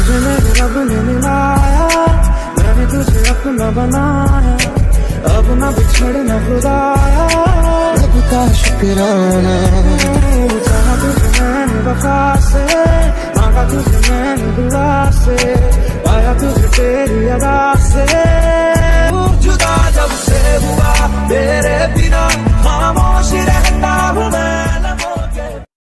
Música Clasica